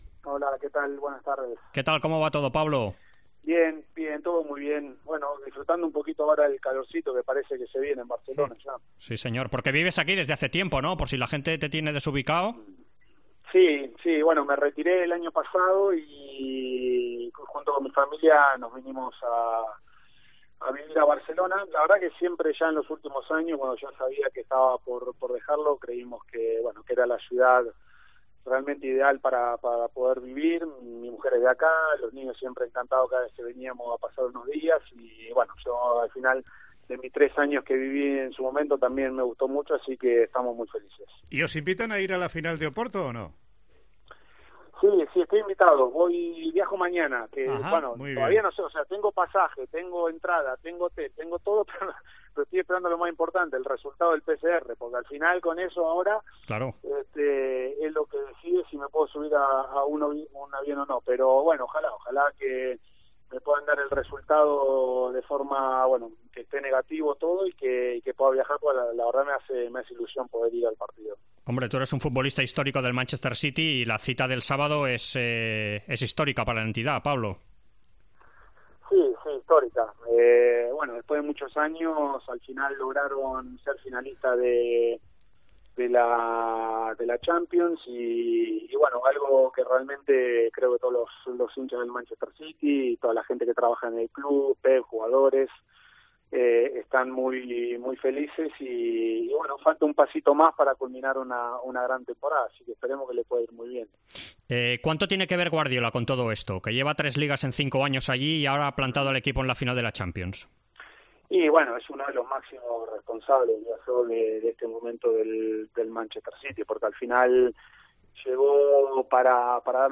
El exjugador del Manchester City Pablo Zabaleta ha dicho en Esports COPE que ve complicado que Guardiola regrese al FC Barcelona y más después de renovar hasta 2023 con el club inglés